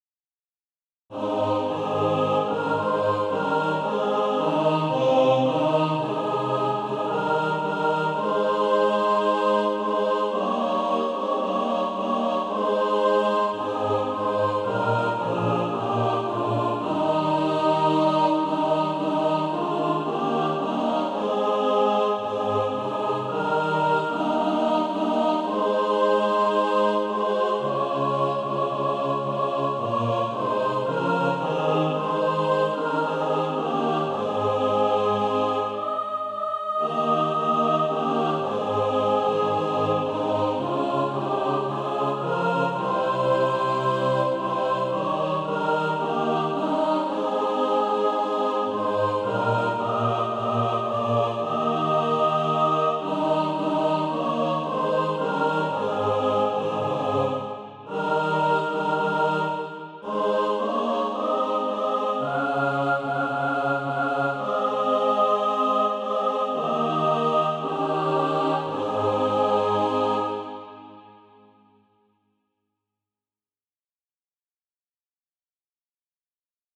a mixed track to practice to
Practice then with the Chord quietly in the background.